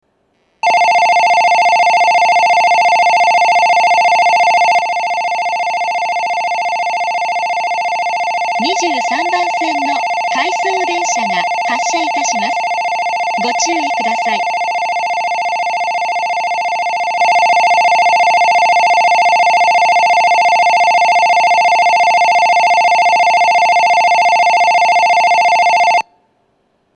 発車時には全ホームROMベルが流れます。
遅くとも東北新幹線全線開業時には、発車ベルに低音ノイズが被るようになっています。ただし、新幹線の音がうるさいので密着収録していてもほとんどわかりません。
２３番線発車ベル 回送電車の放送です。